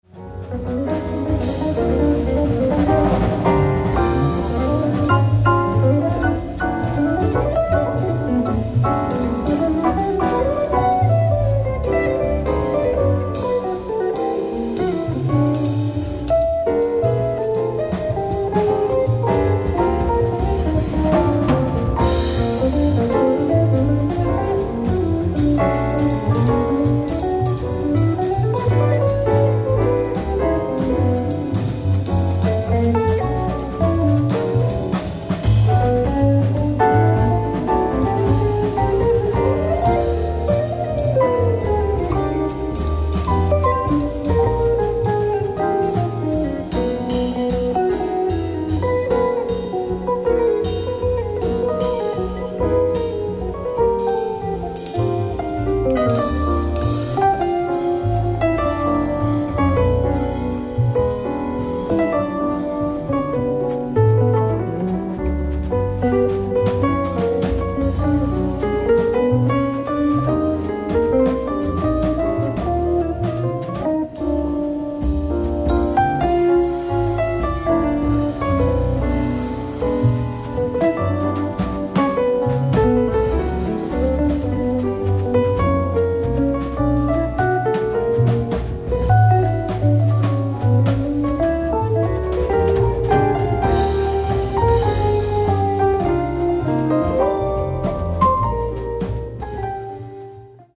ライブ・アット・アルビ、フランス 03/30/1988
※試聴用に実際より音質を落としています。